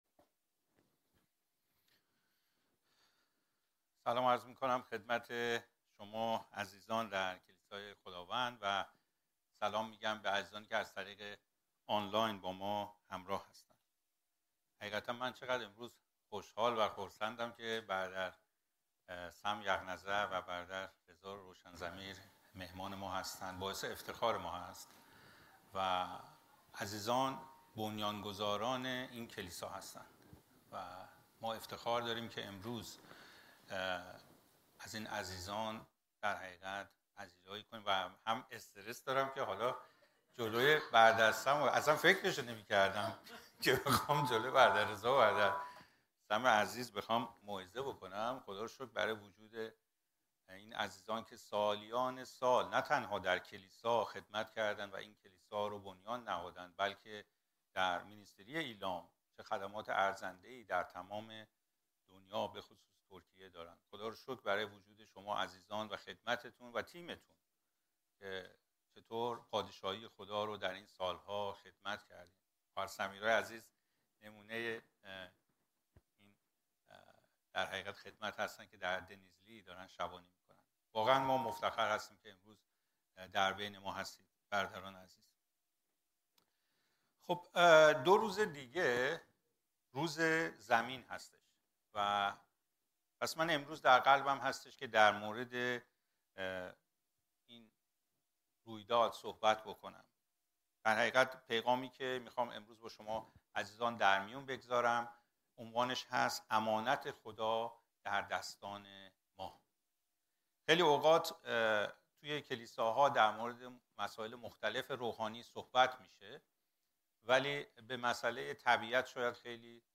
موعظه‌ها